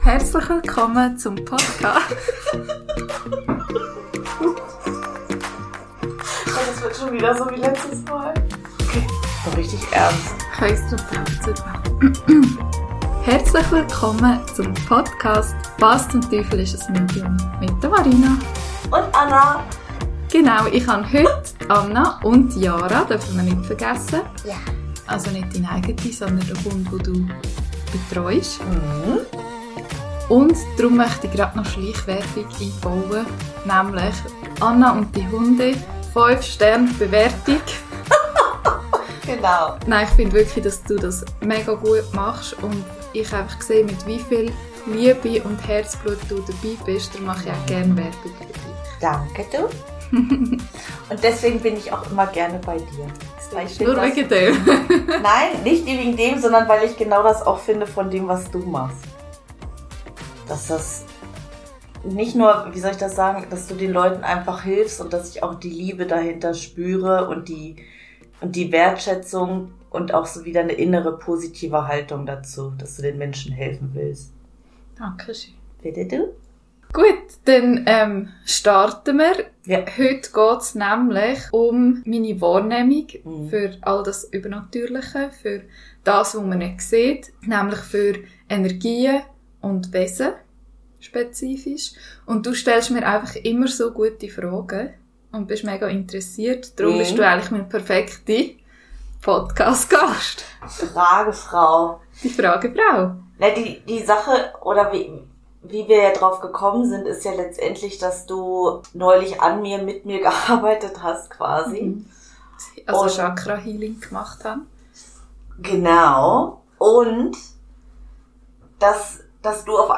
Ein inspirierendes Gespräch über Intuition, Mut und Verbundenheit.